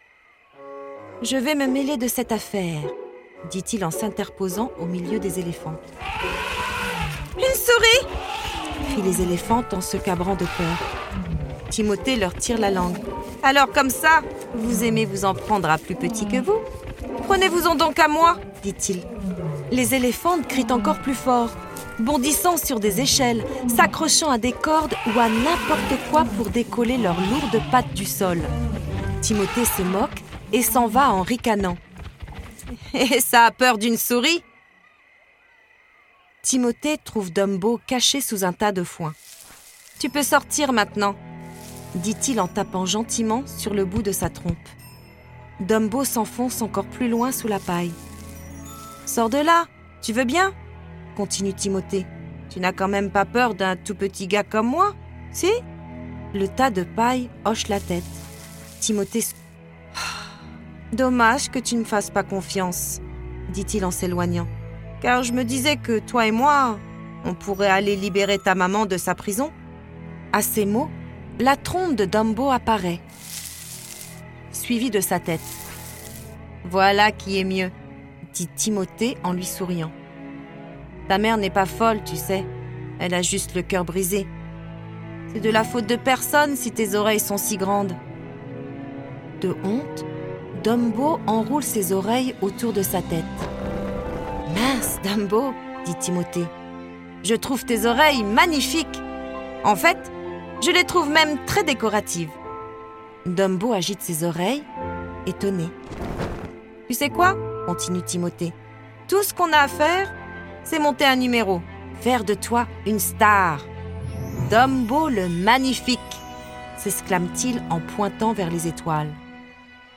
05 - Chapitre 05_ Dumbo - L'histoire à écouter_ Dumbo.flac